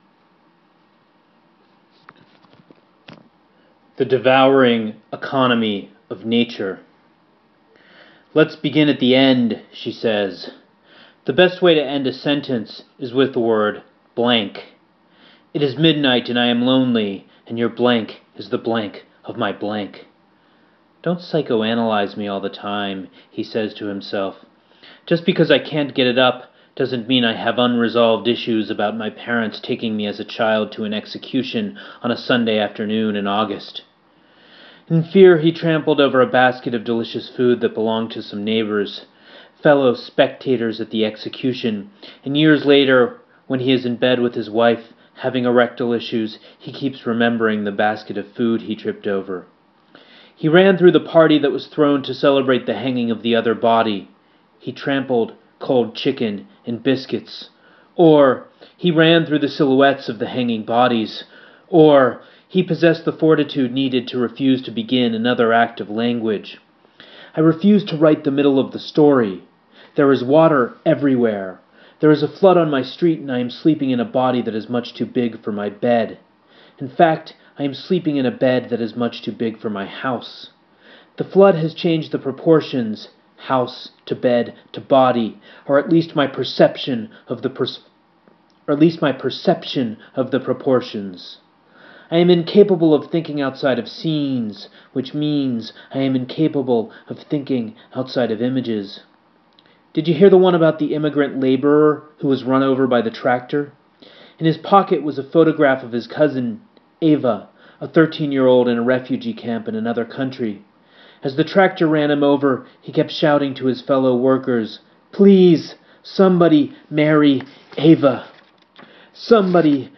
Daniel Borzutzky reads The Devouring Economy of Nature